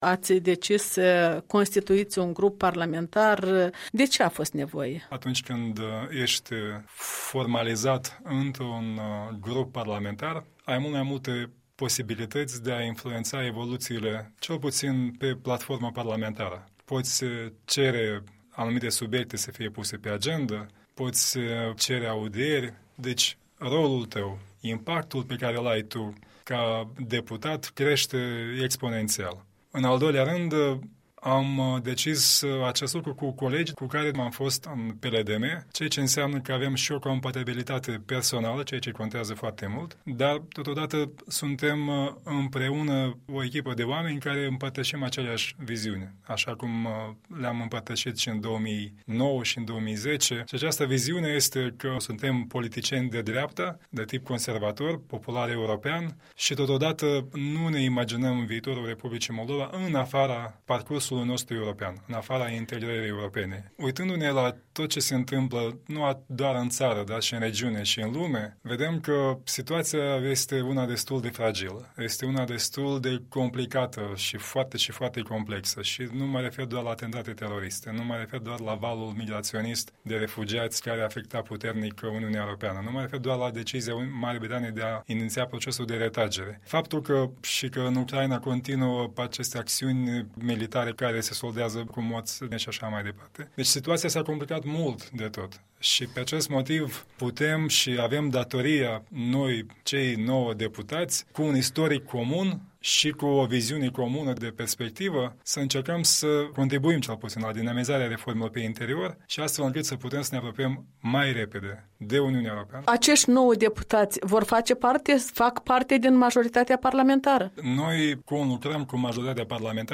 Un interviu cu liderul PPEM.